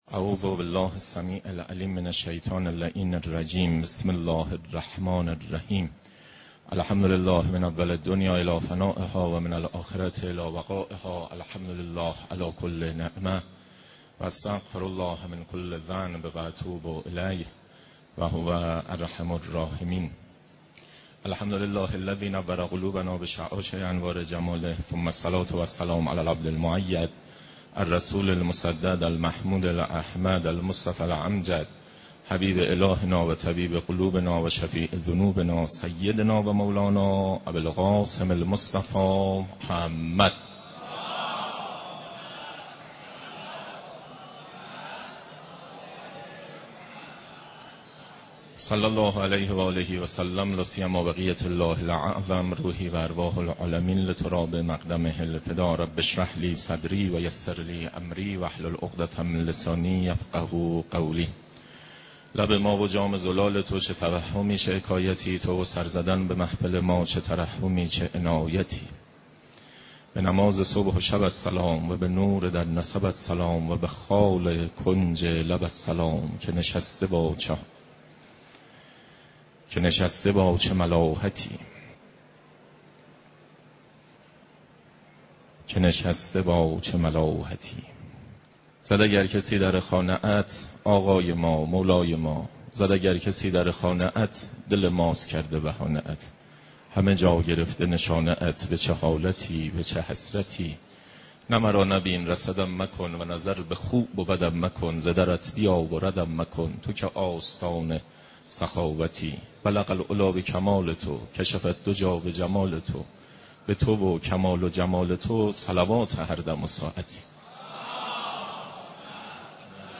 حجم: 7.47 MB | تاریخ: 11 /رمضان/ 1439 | مکان: مسجد مقدس جمکران